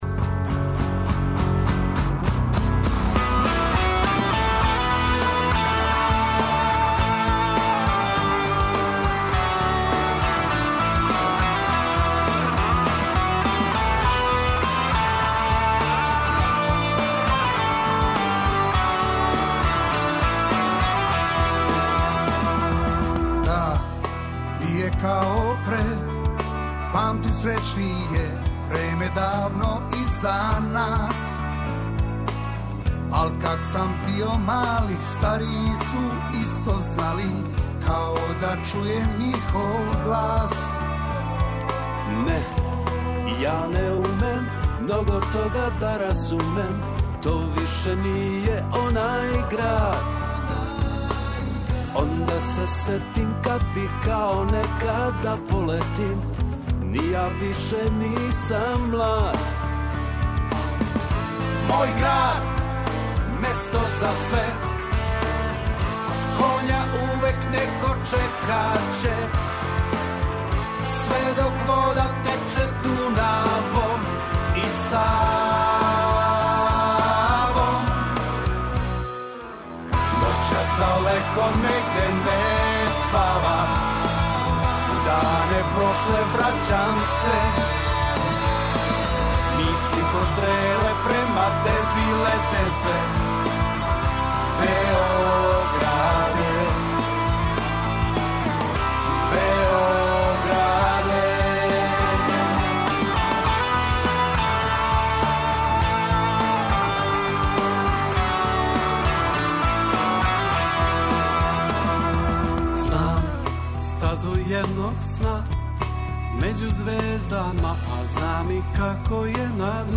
Гост емисије је Никола Чутурило, поводом његове турнеје и концерта у Београду, 8. децембра, у Дому Омладине.